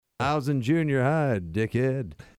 Tags: humor funny sound effects sound bites radio